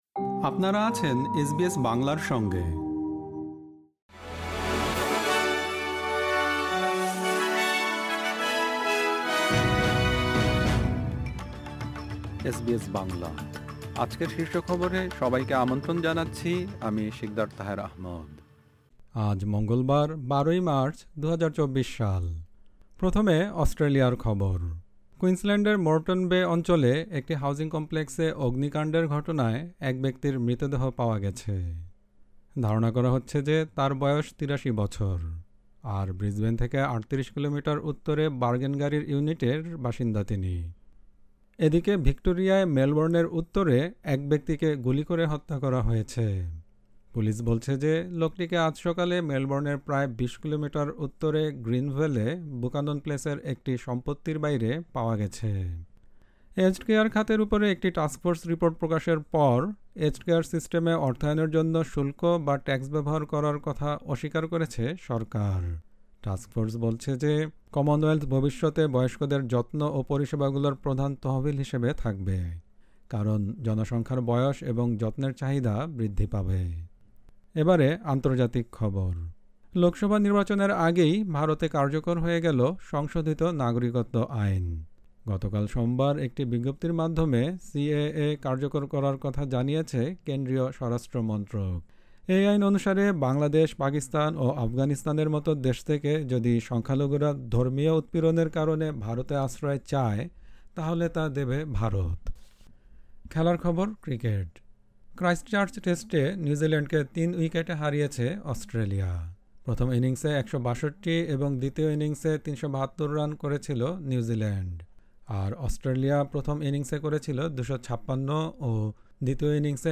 এসবিএস বাংলা শীর্ষ খবর: ১২ মার্চ, ২০২৪